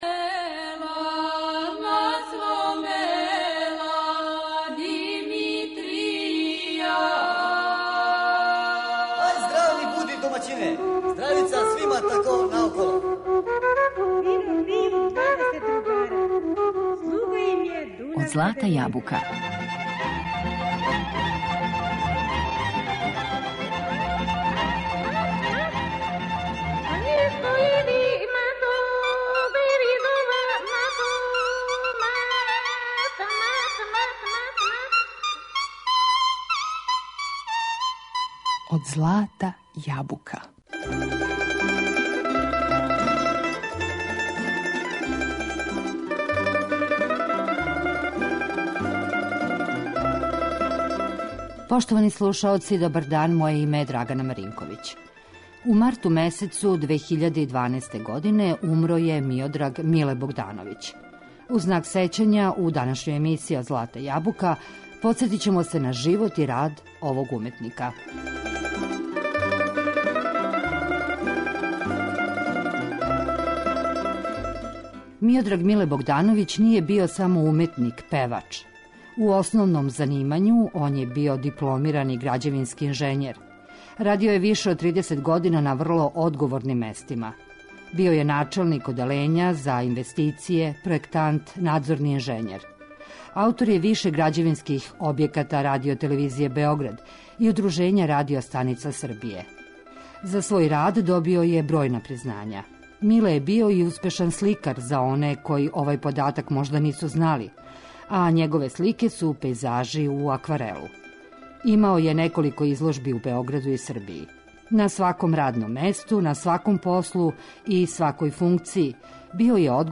У данашњој емисији Од злата јабука, сетићемо се неких детаља из његовог живота и рада, и слушати песме овог уметника.